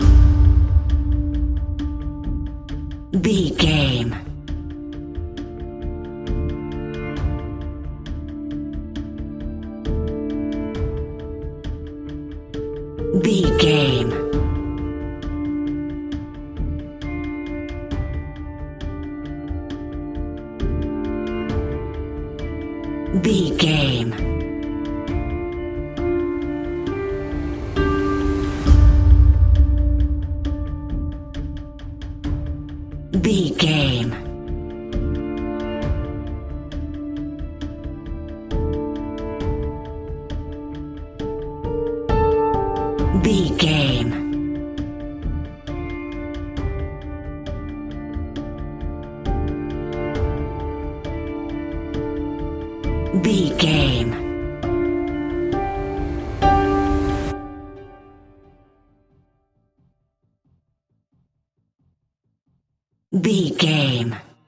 Ionian/Major
D
electronic
techno
trance
synthesizer
synthwave
instrumentals